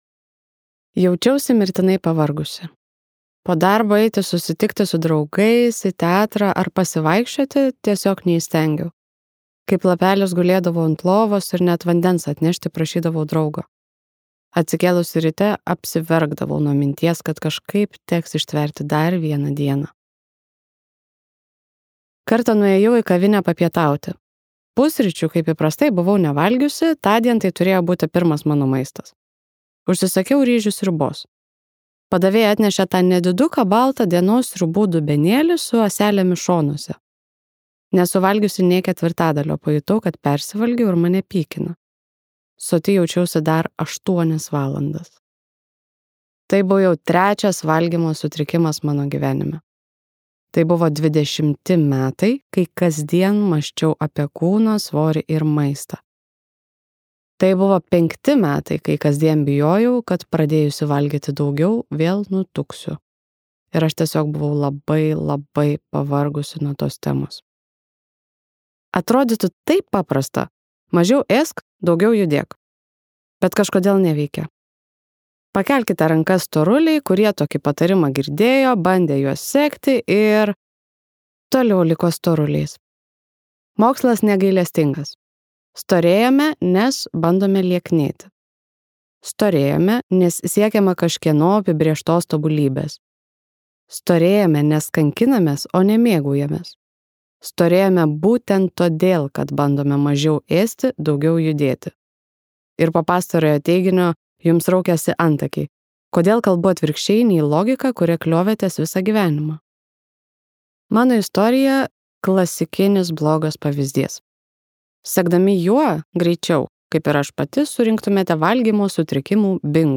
Mano kelionė iki 100 kg ir atgal | Audioknygos | baltos lankos